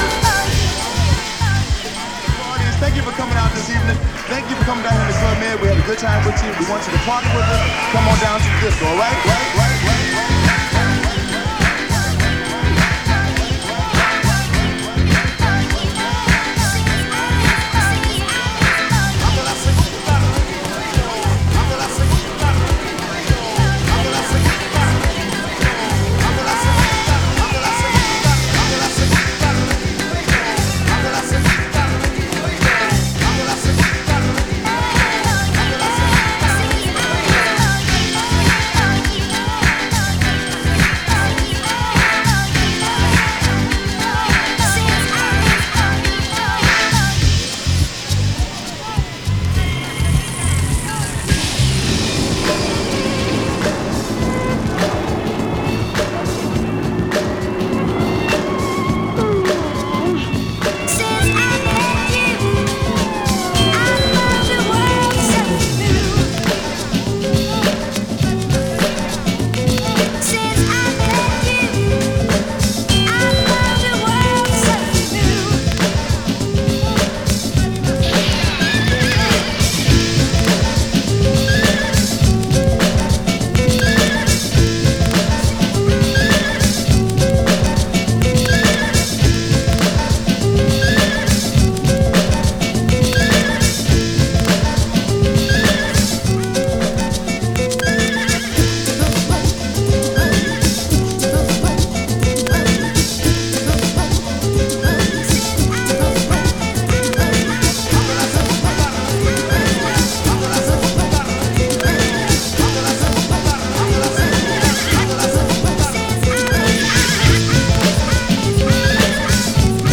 Жанр: electronic, hip hop, downtempo